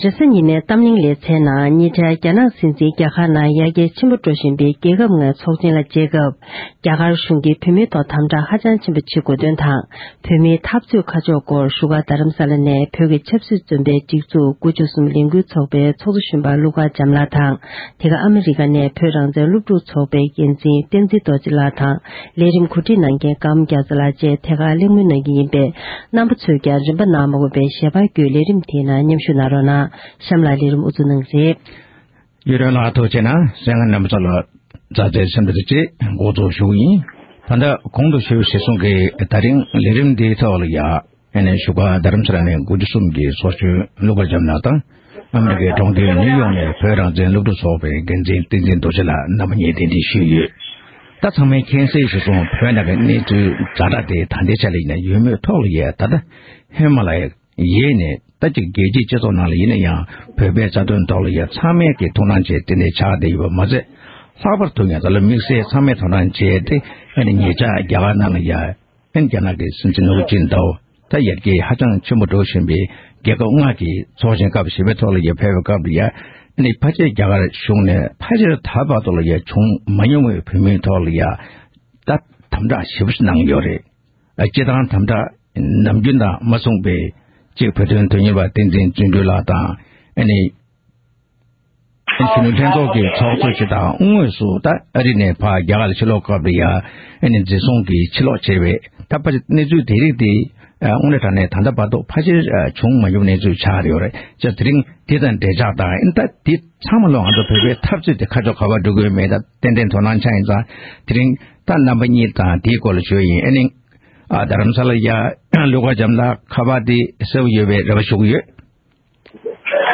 གཏམ་གླེང